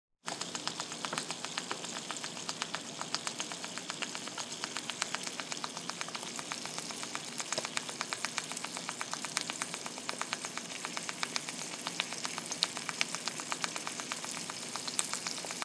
Frying Eggs
frying-eggs.m4a